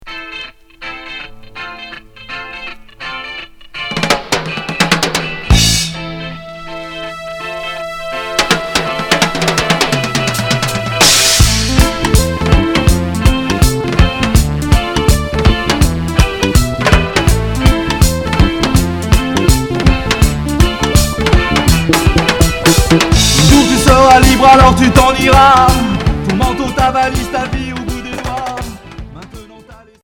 Reggae rock Unique 45t